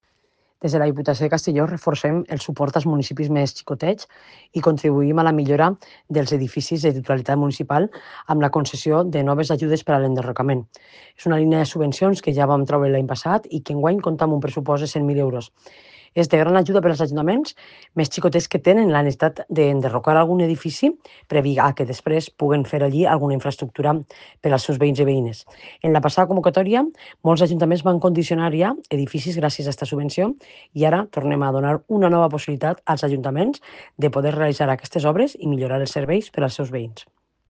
Presidenta-Marta-Barrachina-ayudas-derribos.mp3